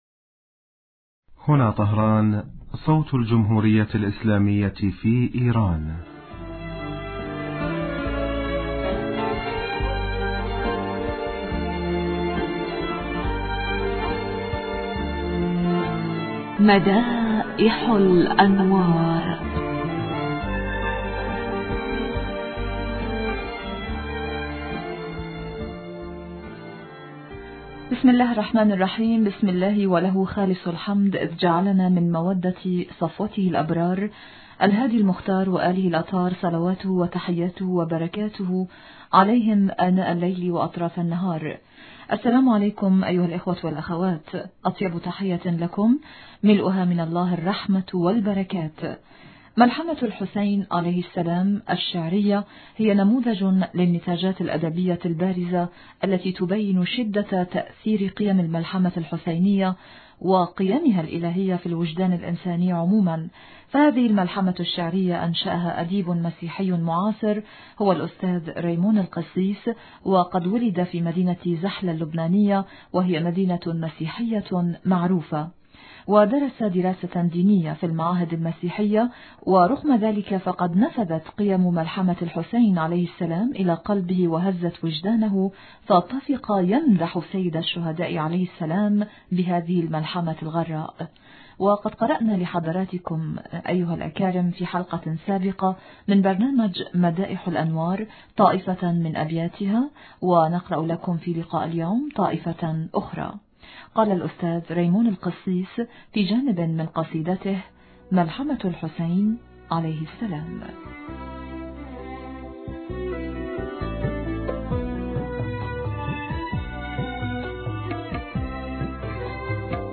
إذاعة طهران- مدائح الانوار: الحلقة 641